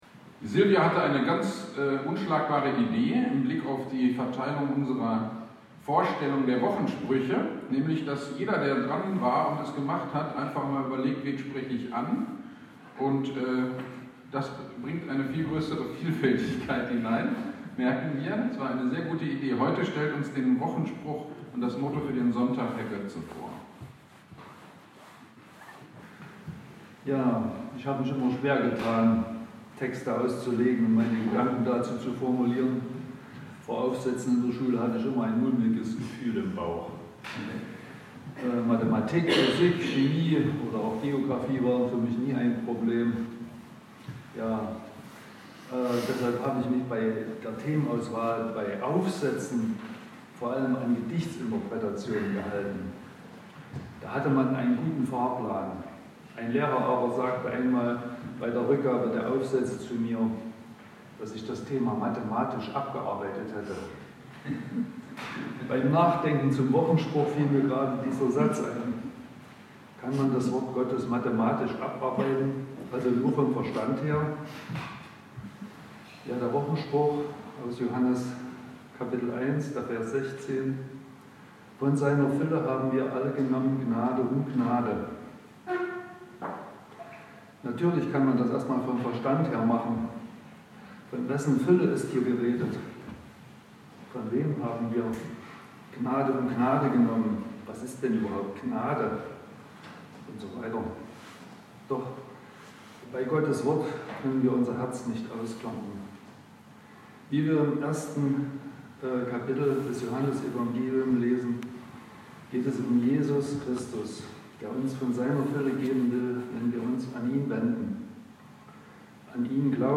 GD am 16.01.2022 Predigt zu 1. Korinther 2.1-8 - Kirchgemeinde Pölzig